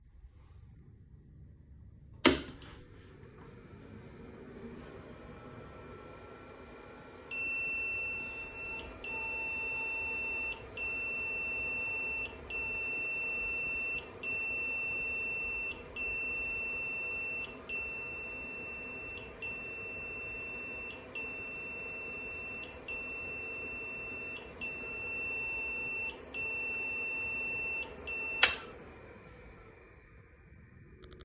Some time later, a never-ending series of long beeps starts.
The time from power-on until the sound appears depends on the amount of conventional memory that is fitted.
IBM 5150 - POST - never-ending series of long beeps.mp3